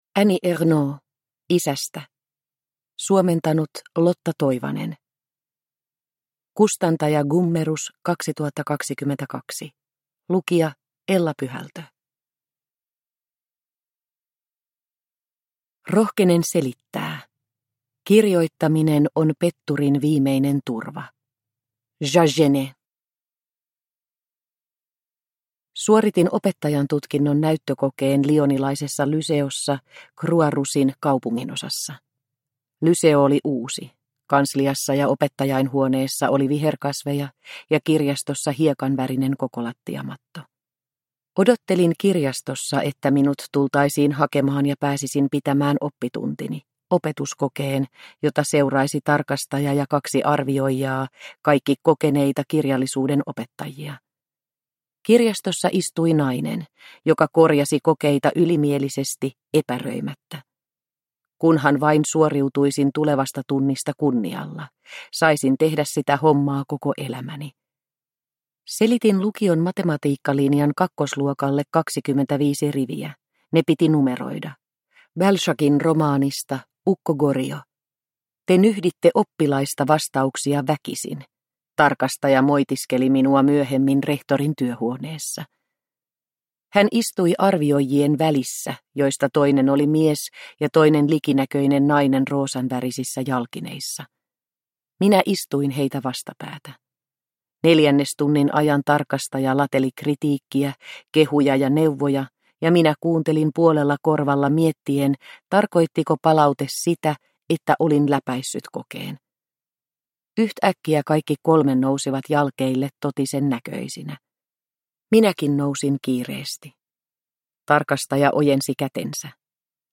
Isästä – Ljudbok – Laddas ner